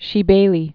(shē-bālē, shə-)